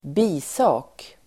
Uttal: [²b'i:sa:k]